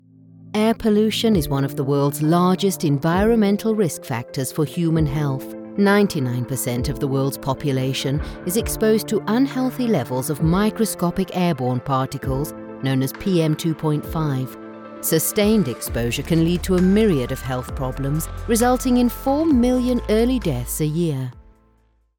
Female
Explainer Videos
Serious & Credible
0411AirPollution_Informative_Intelligent_music.mp3